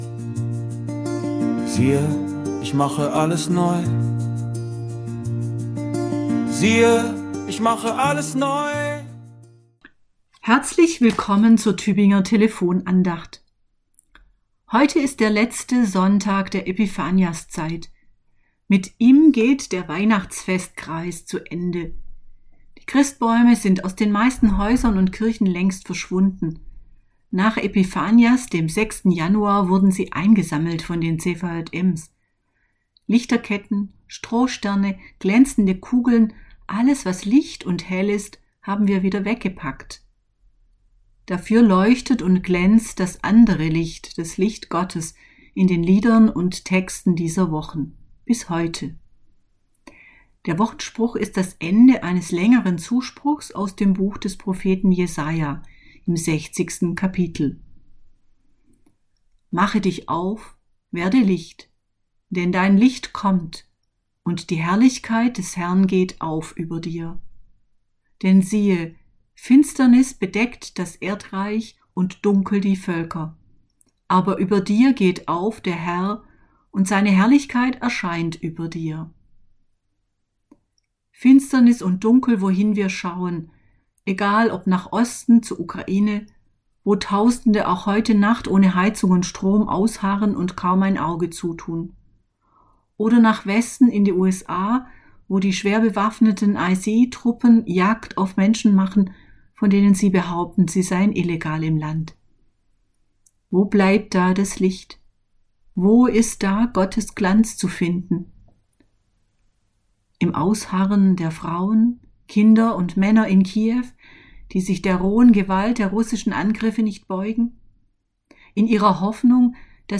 Andacht aus dem Januar